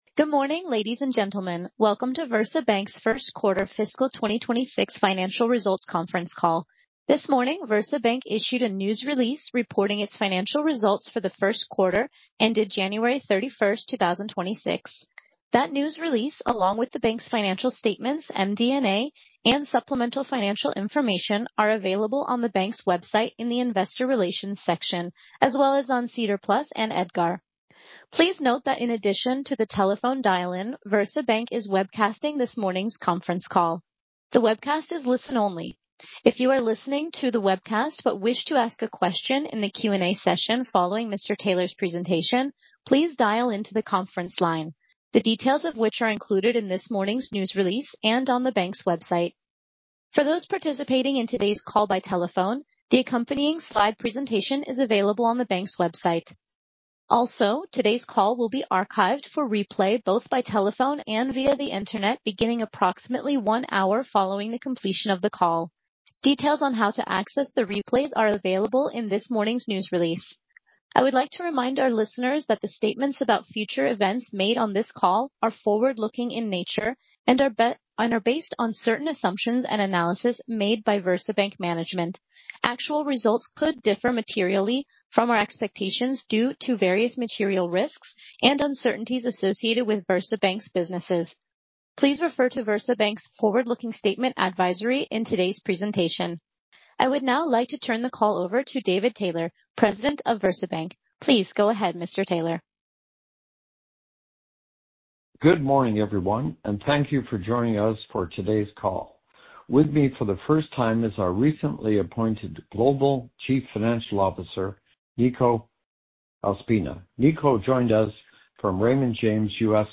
2026-q1-call-recording.mp3